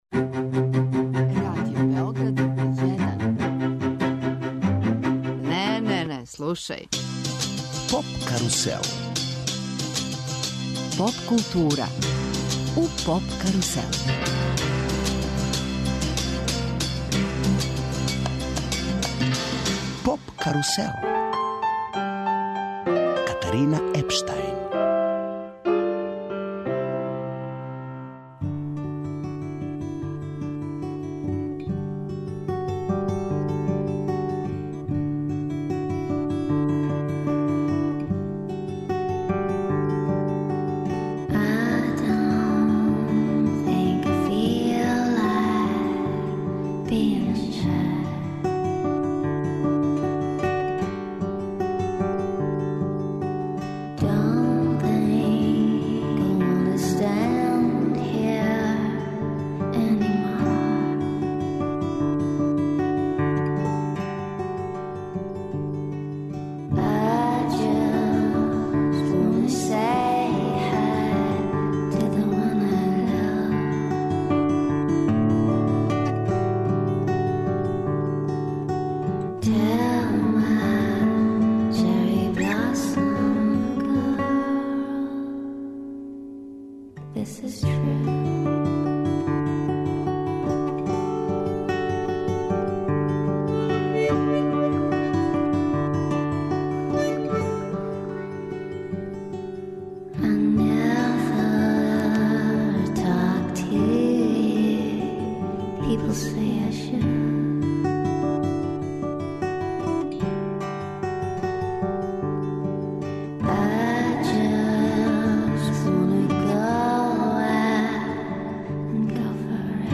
Представљамо вам састав THE GONDORS, млади састав, чија је база у Берлину. Њихова музика заснована је на утицајима Лу Рида, Битлса, Дејвида Боувија, Френка Запе, Џими Хендрикса и стилски је психоделични поп.
Емисија из домена популарне културе.